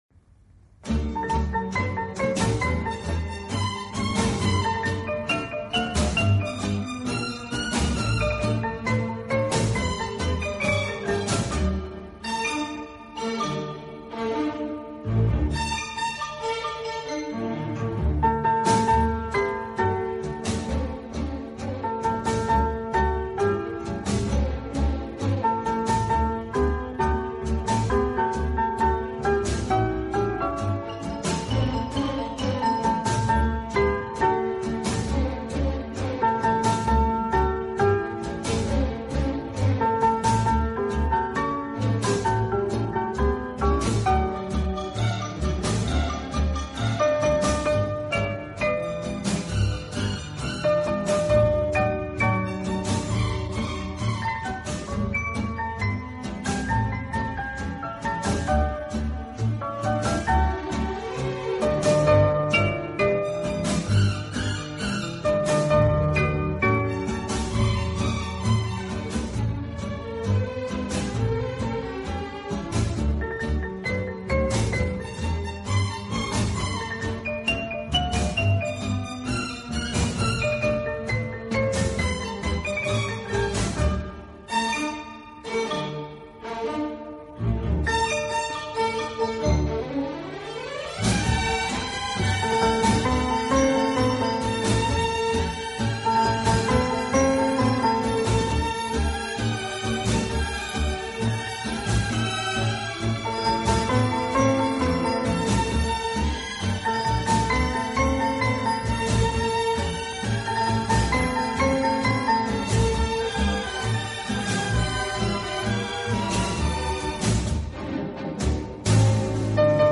【轻音乐专辑】
演奏以轻音乐和舞曲为主。